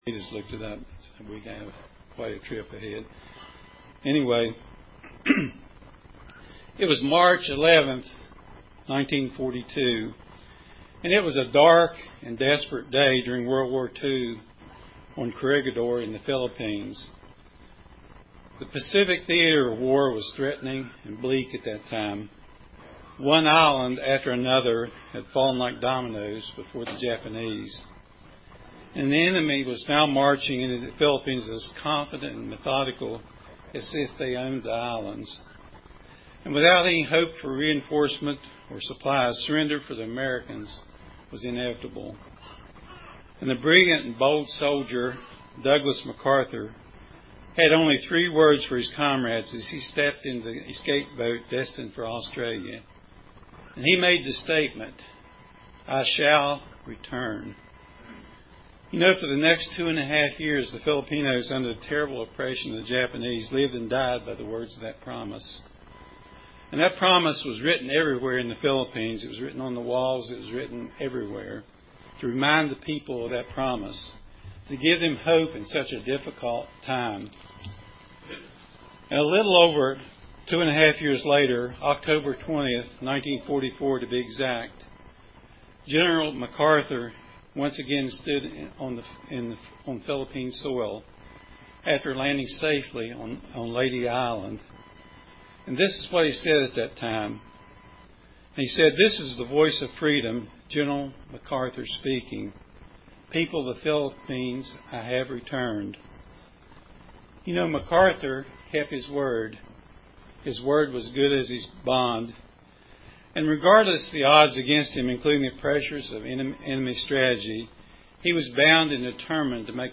Given in Paintsville, KY